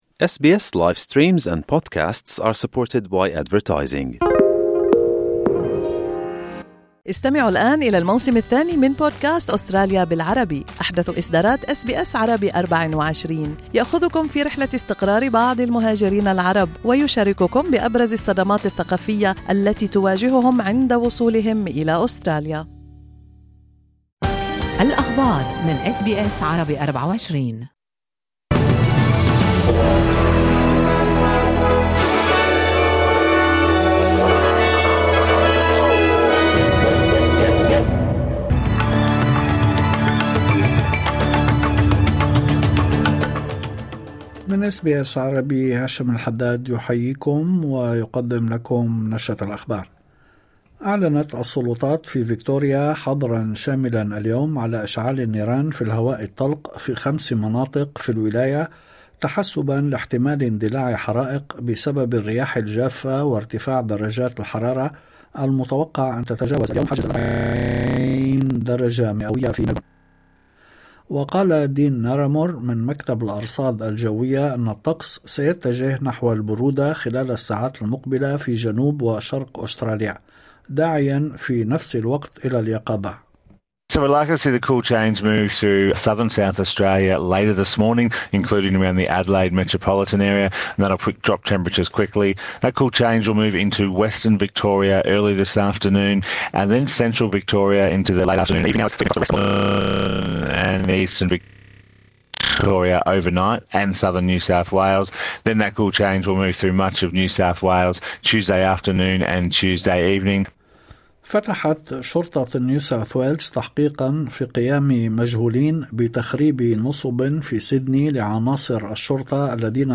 نشرة أخبار الظهيرة 27/01/2025 5:47